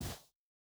Shoe Step Snow Medium C.wav